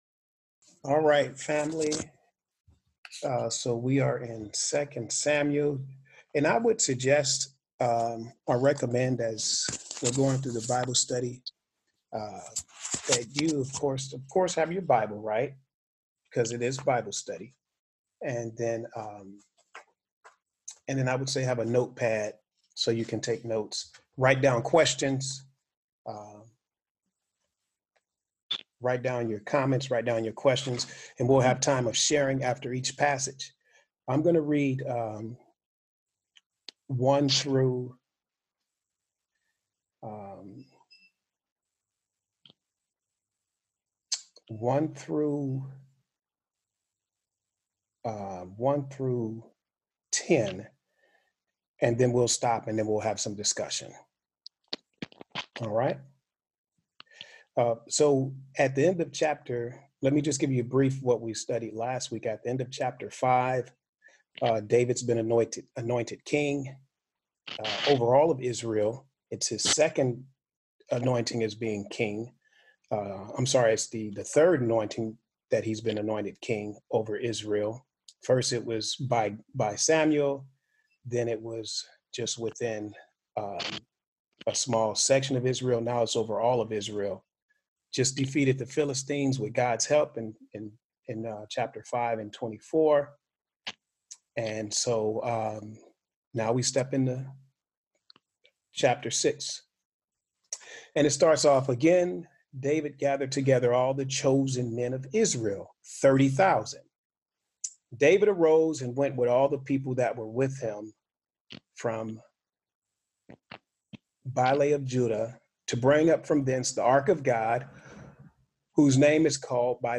Bible-Study-3-25-20.mp3